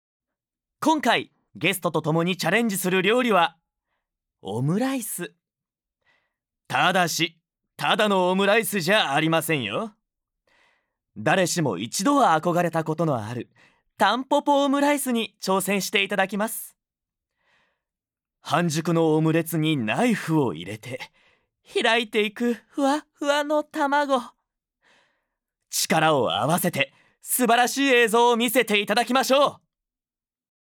ボイスサンプル
●ナレーション①勢いのあるバラエティノリ